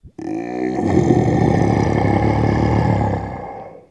Human Vocals
Monster Growl Dinosaurs and Relic Human Voice Pitched